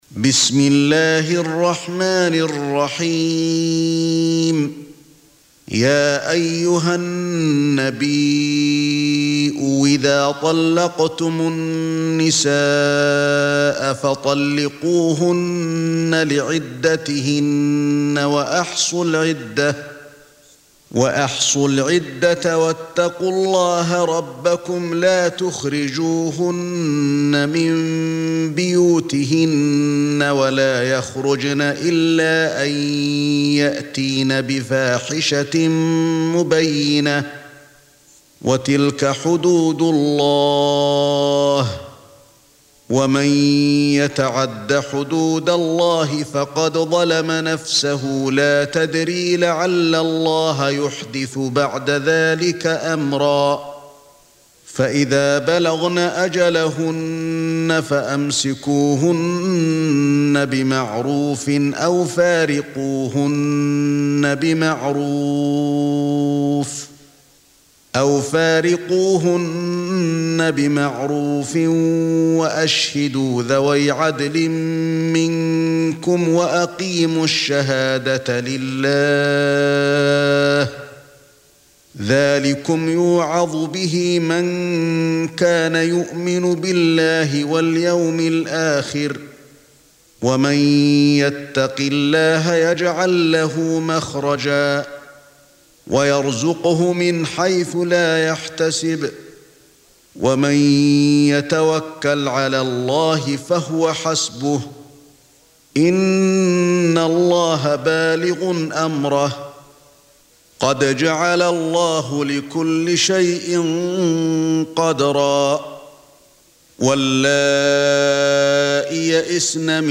Audio Quran Tarteel Recitation
Surah Sequence تتابع السورة Download Surah حمّل السورة Reciting Murattalah Audio for 65. Surah At-Tal�q سورة الطلاق N.B *Surah Includes Al-Basmalah Reciters Sequents تتابع التلاوات Reciters Repeats تكرار التلاوات